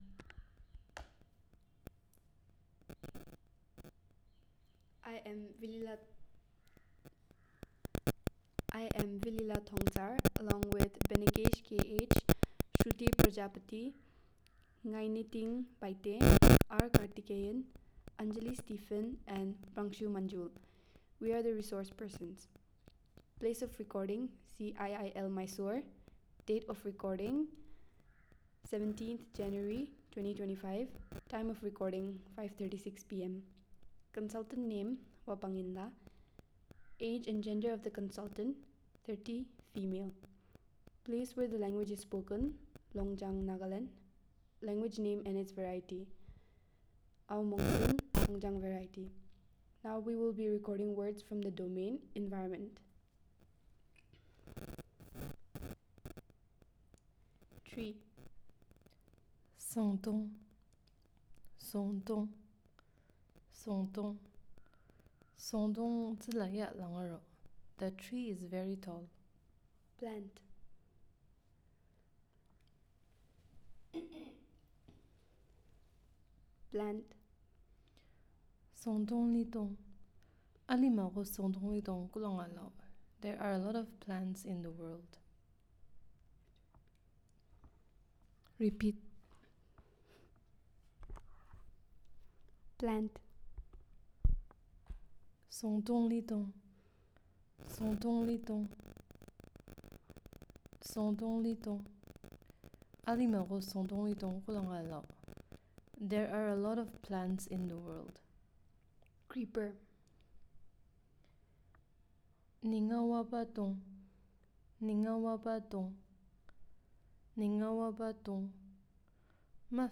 Elicitation of sentences on the domain of Environment